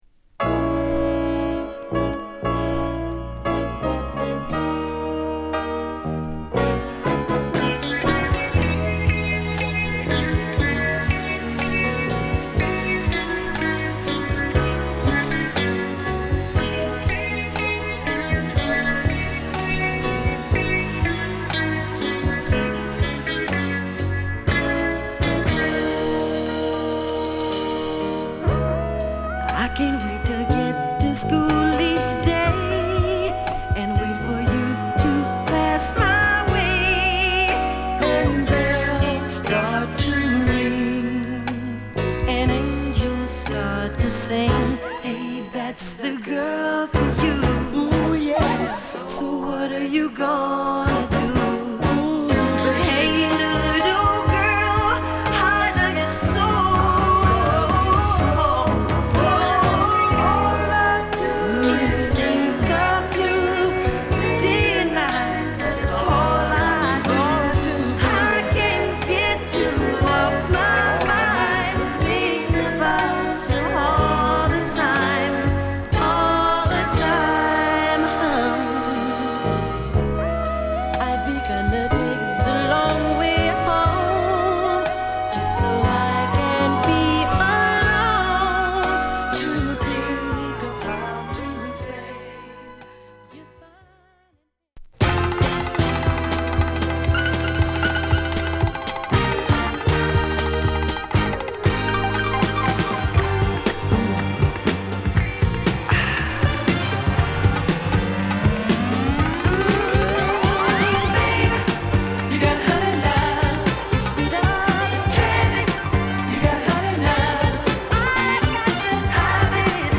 哀愁のギターメロが切な過ぎる１曲です。